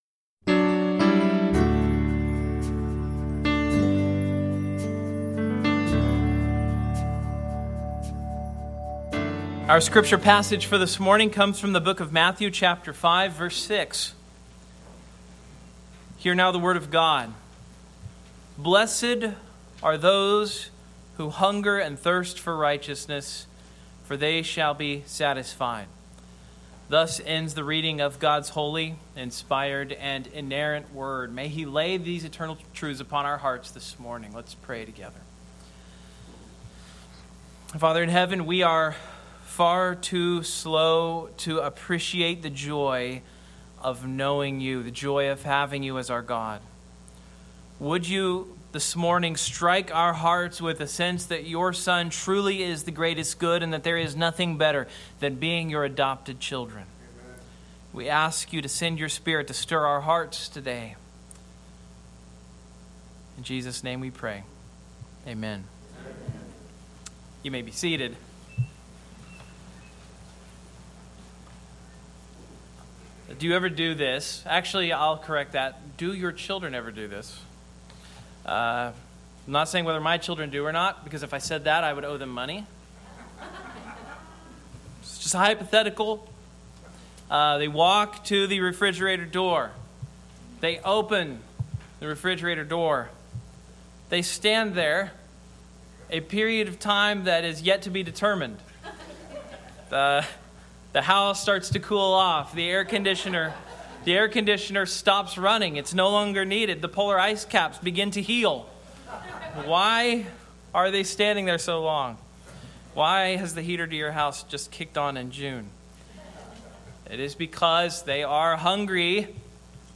Service Type: Morning Sermon